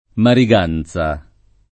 [ mari g# n Z a ]